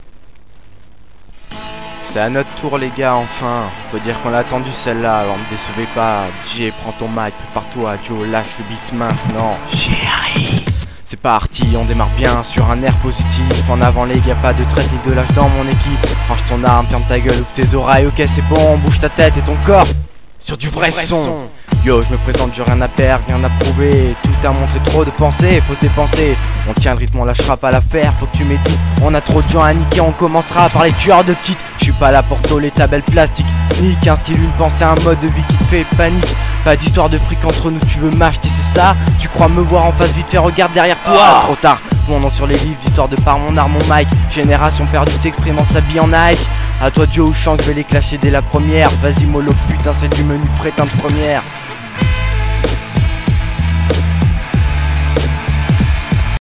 Nos trois rappeurs, s'entrainent dur à la rime entre novembre et janvier, vint alor leur premiers essais audio sur instrumentale empruntée, après tout, ce n'est que de l'entraînement...
une intro